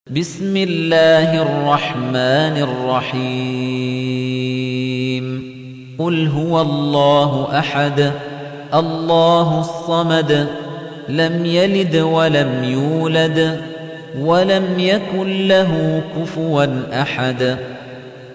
تحميل : 112. سورة الإخلاص / القارئ خليفة الطنيجي / القرآن الكريم / موقع يا حسين